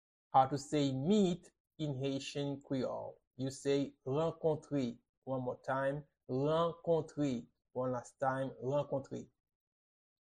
How to say "Meet" in Haitian Creole - "Rankontre" pronunciation by a native Haitian Creole teacher
“Rankontre” Pronunciation in Haitian Creole by a native Haitian can be heard in the audio here or in the video below: